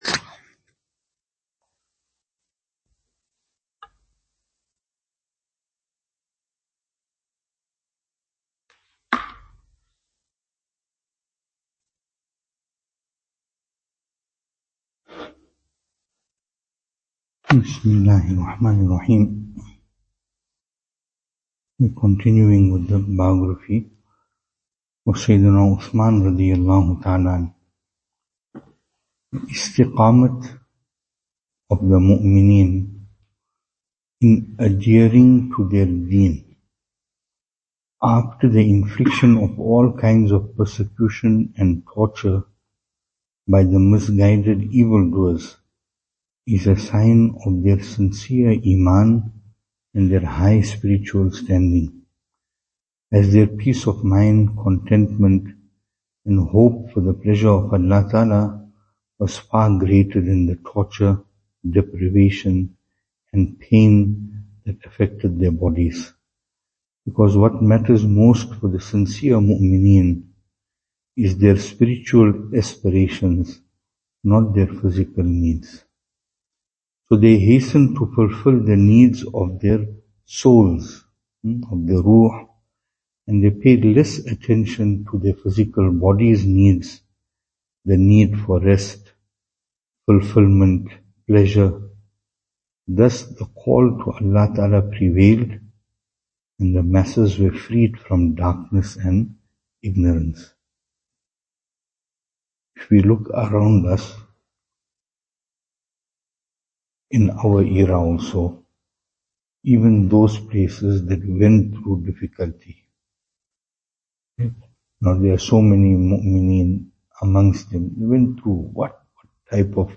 Venue: Albert Falls , Madressa Isha'atul Haq
Service Type: Majlis